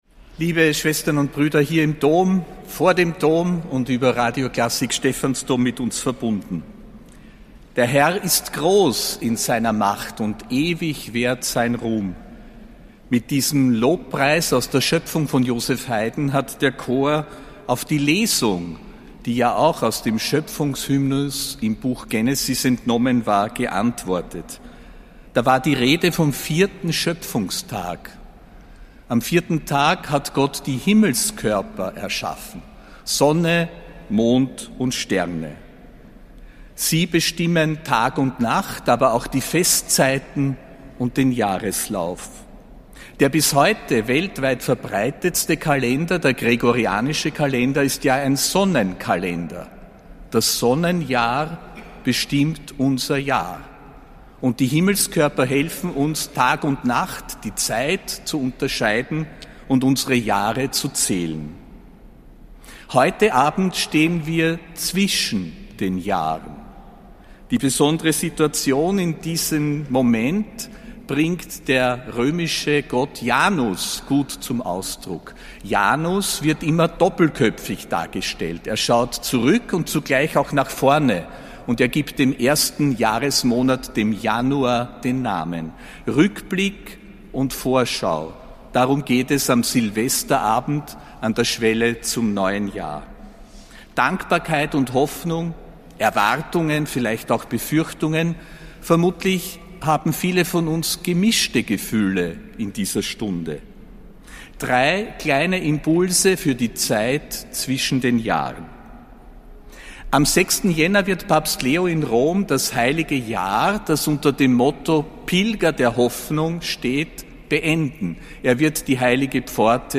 Ansprache des Ernannten Erzbischofs Josef Grünwidl zur Jahresschlussfeier, am 31. Dezember 2025.